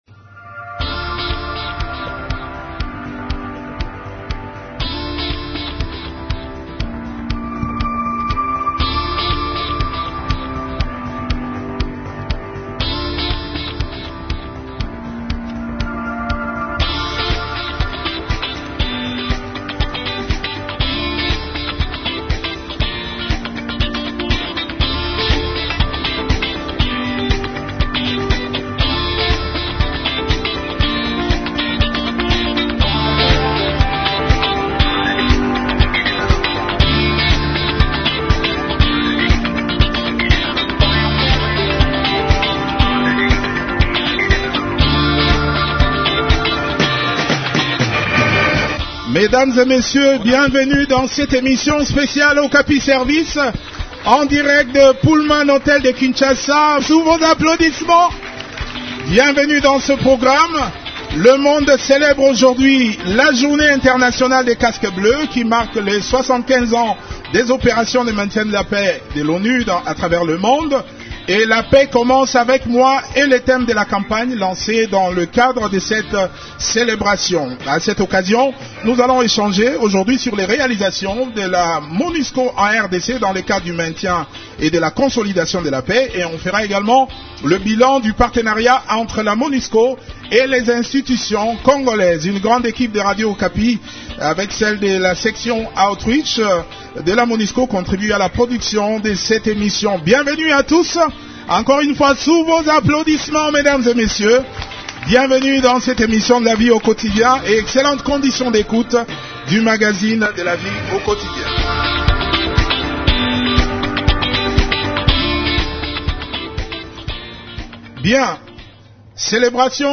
Célébration ce lundi 29 mai 2023 de la journée internationale des casques bleus qui marque les 75 ans des opérations de maintien de la paix de l’ONU à travers le monde depuis sa création en 1948. A cette occasion, les représentants des différentes sections de la Monusco ont parlé de leurs missions et réalisations au cours d’une émission spéciale à Pullman Hôtel de Kinshasa.